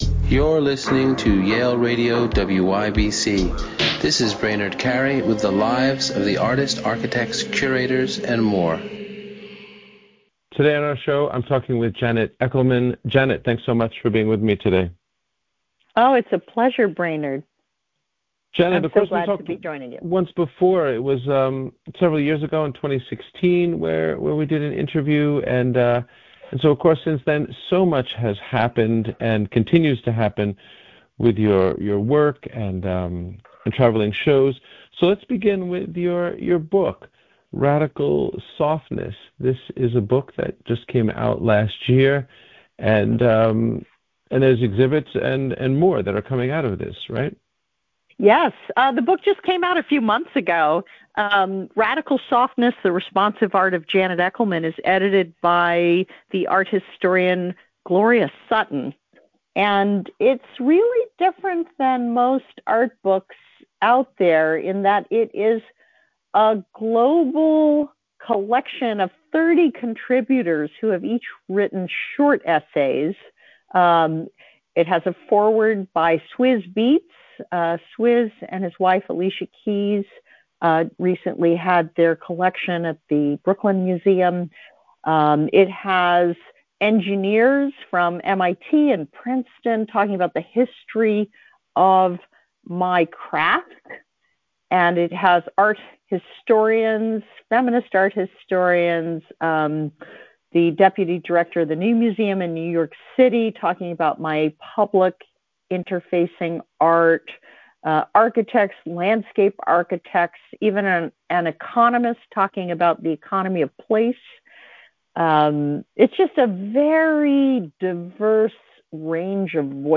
Janet Echelman | Interviews from Yale University Radio WYBCX